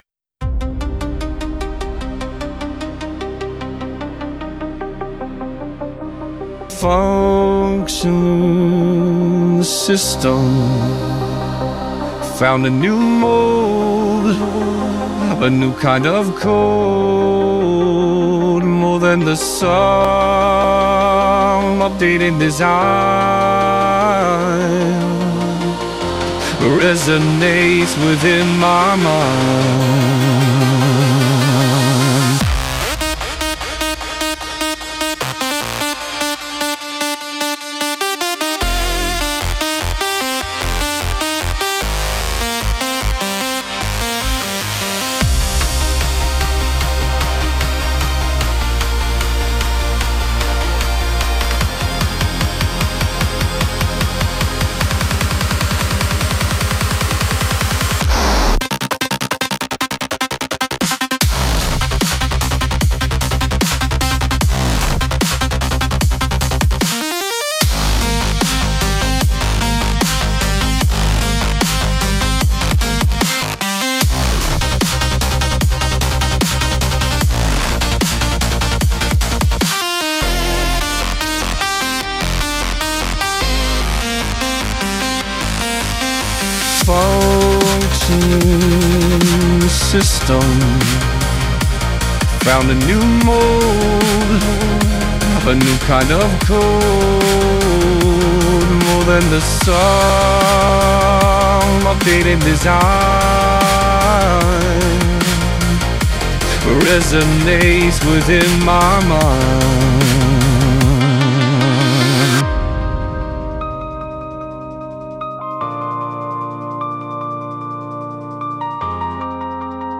System-Function(Dubstep-Edit).wav